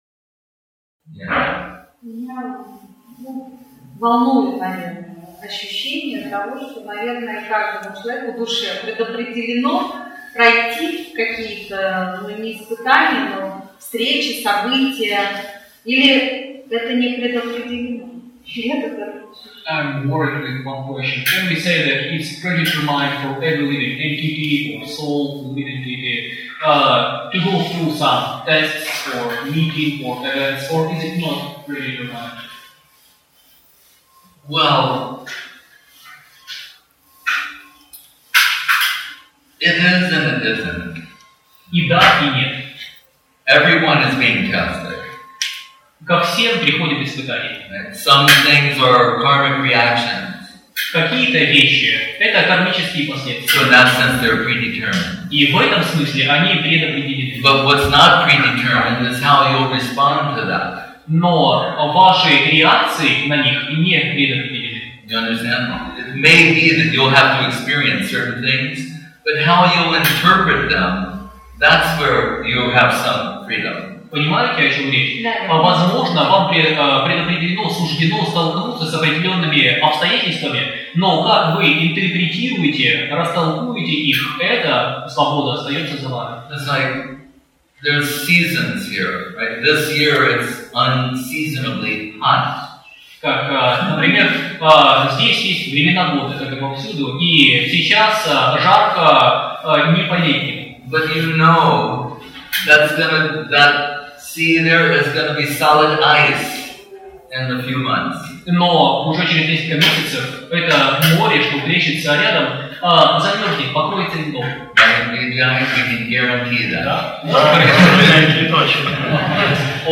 Place: Centre «Sri Chaitanya Saraswati» Moscow